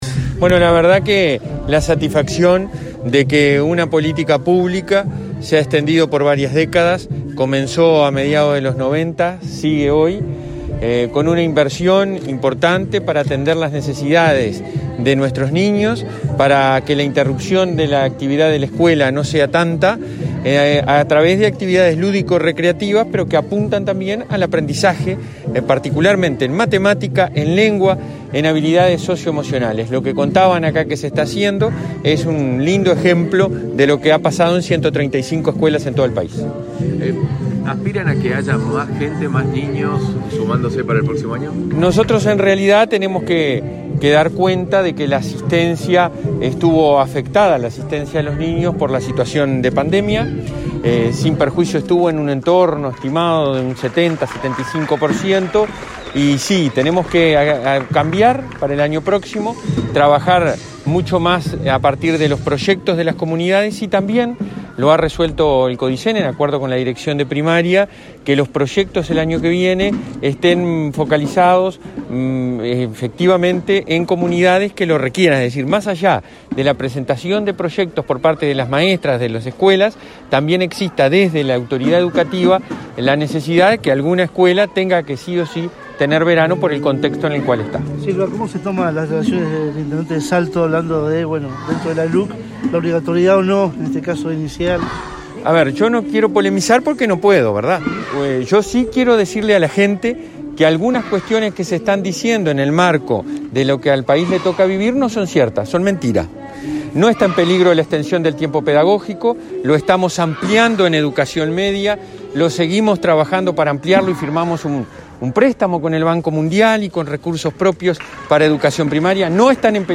Declaraciones del presidente de ANEP a la prensa
El titular del Consejo Directivo Central (Codicen) de la Administración Nacional de Educación Pública (ANEP), Robert Silva, participó este jueves 10